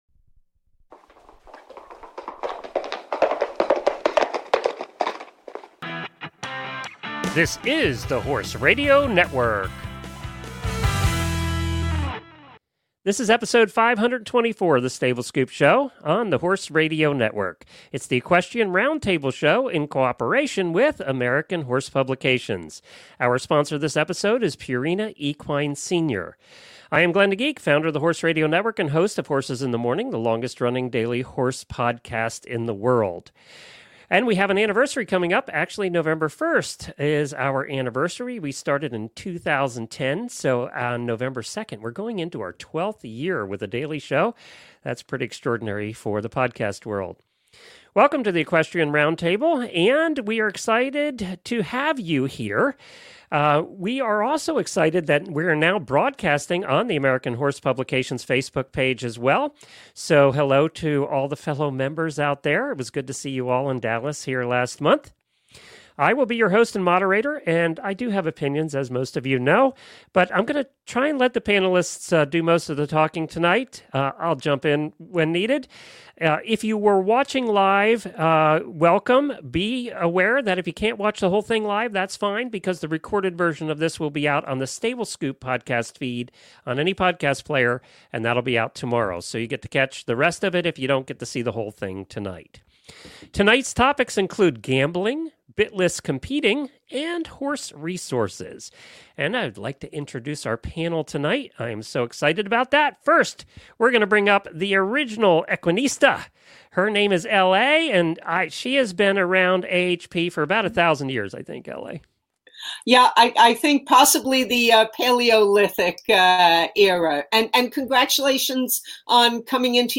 Stable Scoop AHP Roundtable for Oct. 21, 2021 by Purina Senior Feed